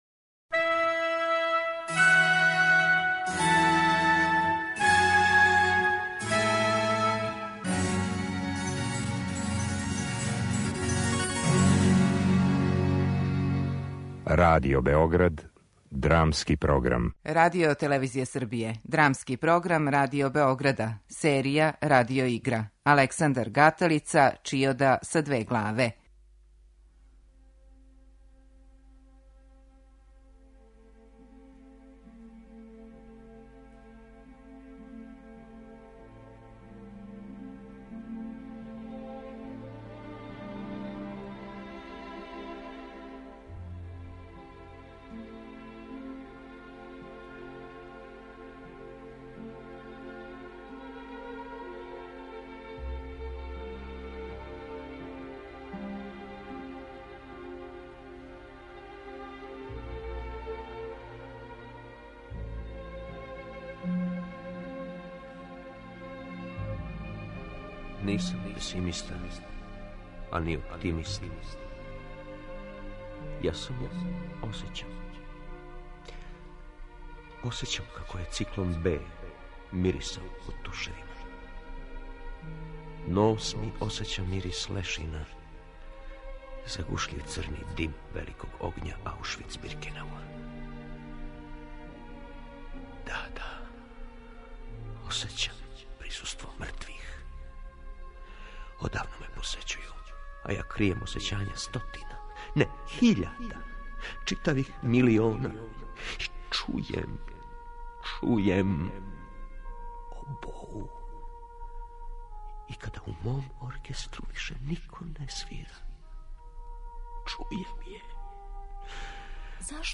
Радио игра